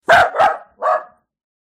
「わんわん わん」。
子犬の鳴き声「わんわんわん」 着信音